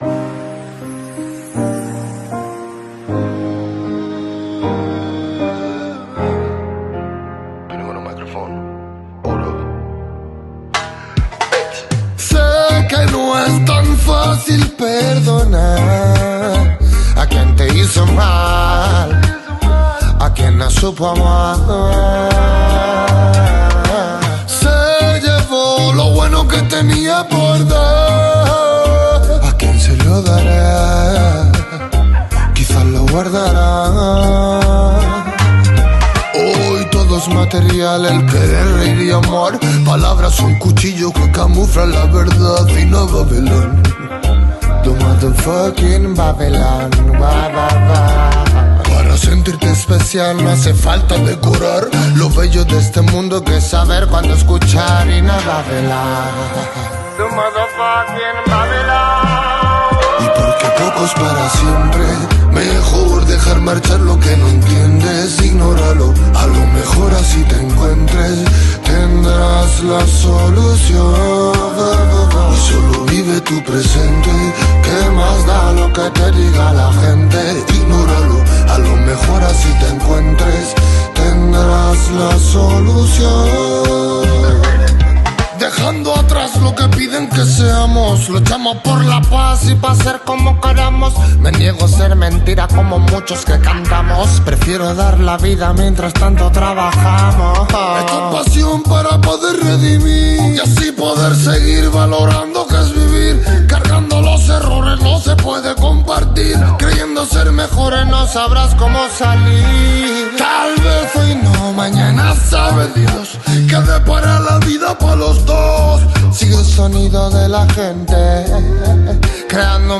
🎙 Podcast – Intervista
Un incontro intenso, realizzato sul ponte più alto dell’imbarcazione, con lo sguardo rivolto alle tante navi pronte a salpare.